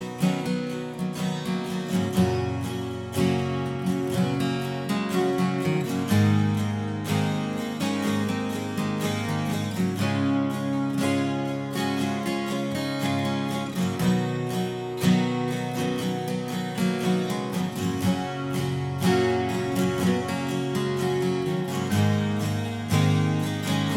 Minus Lead Guitar Rock 5:46 Buy £1.50